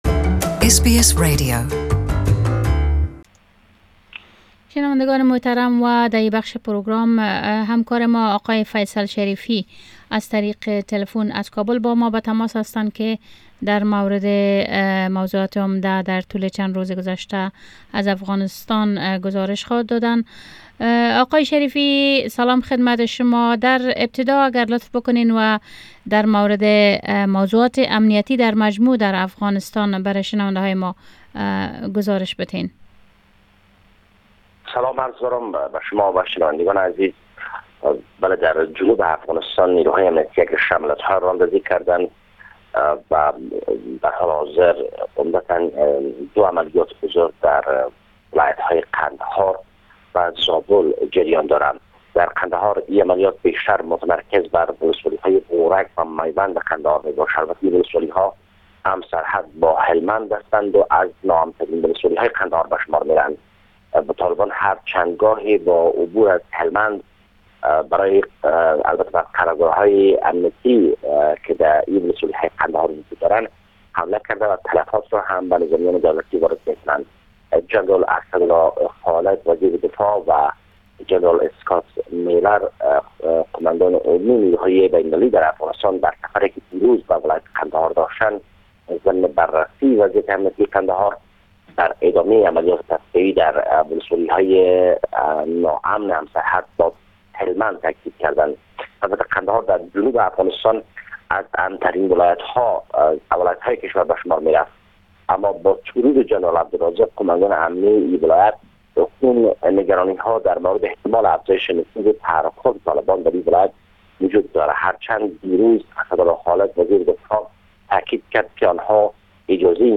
Report from Afghanistan 2nd February